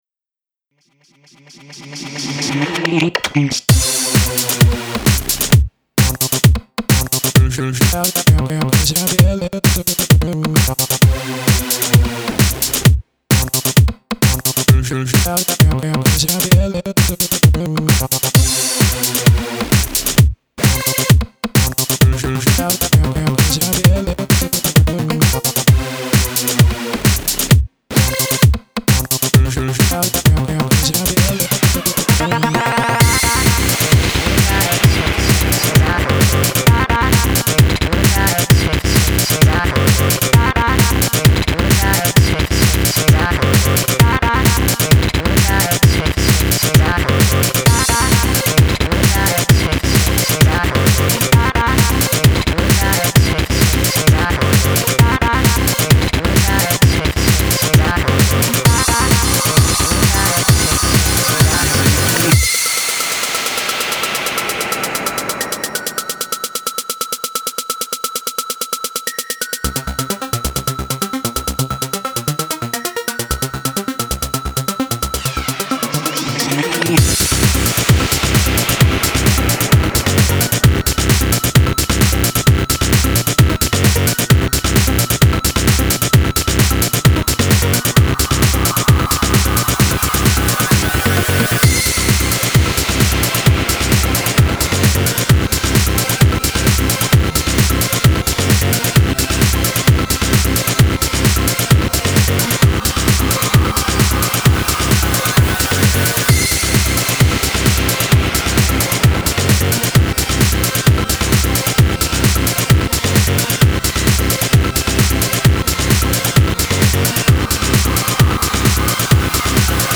Style : Dance